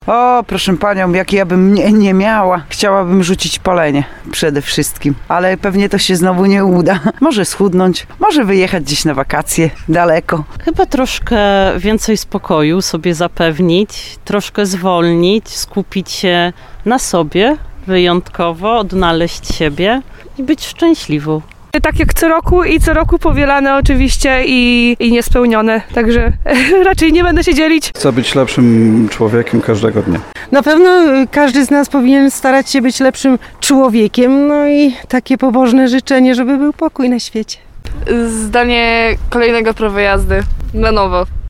O postanowienia noworoczne spytaliśmy ełczan.
Posłuchajcie naszej sondy.